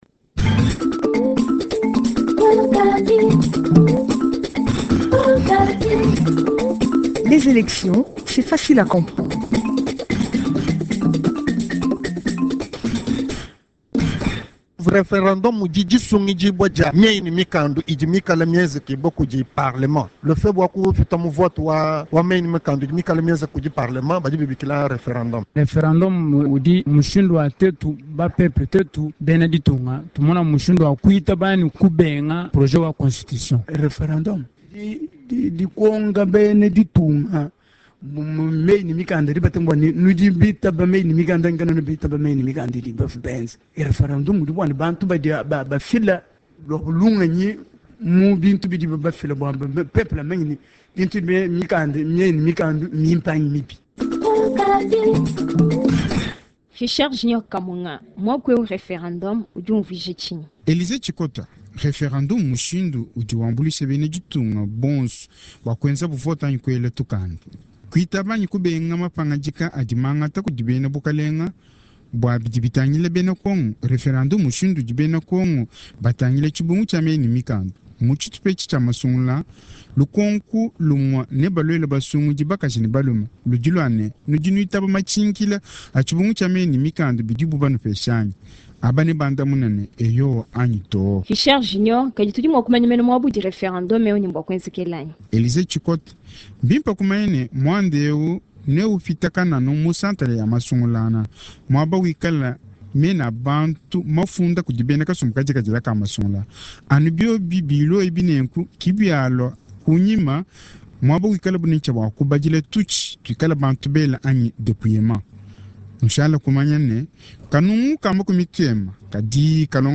Chronique ” les